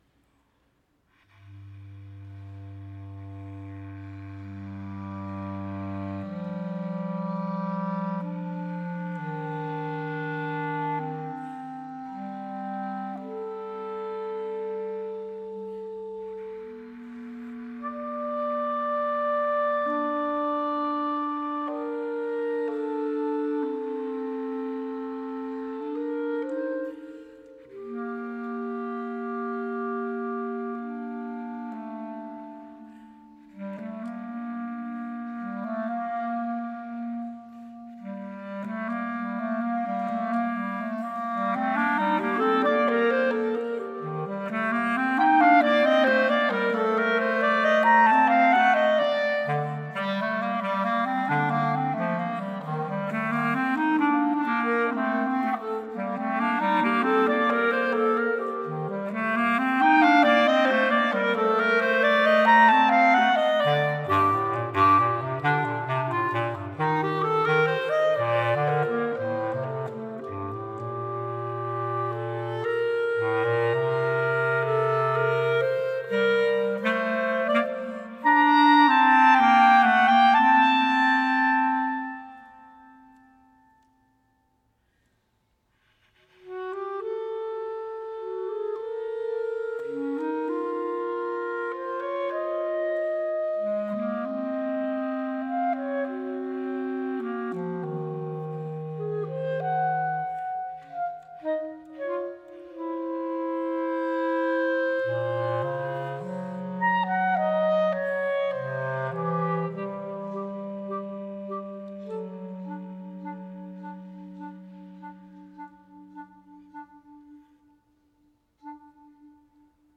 duet for two clarinets
for two clarinets